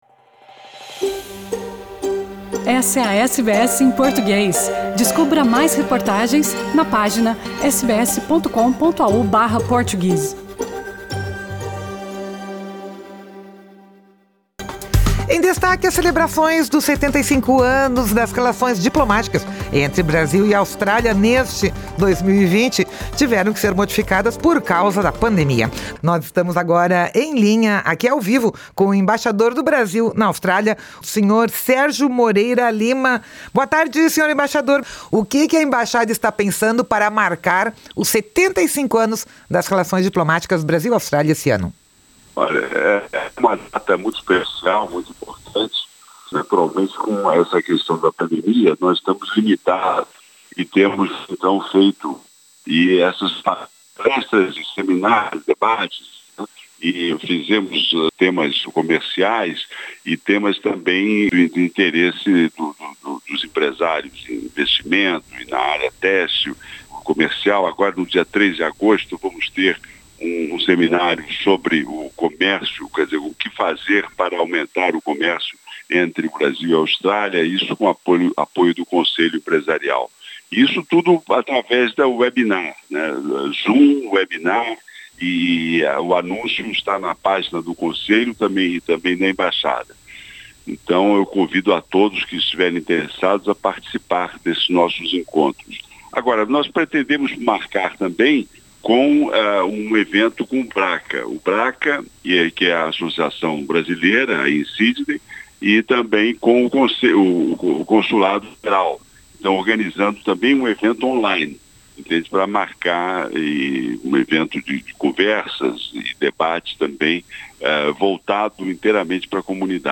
Conversamos com o embaixador do Brasil na Austrália, Sérgio Moreira Lima, sobre a data. As festas não ocorrem por conta do coronavírus, mas há diversos eventos online.